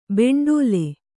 ♪ beṇḍōle